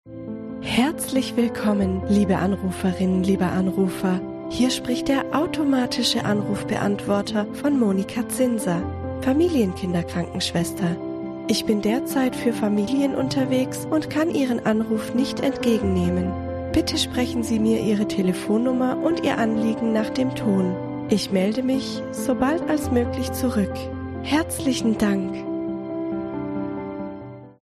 Ihre Stimme für Telefonansagen & Warteschleifen
Professionelle Sprecherin
Anrufbeantworter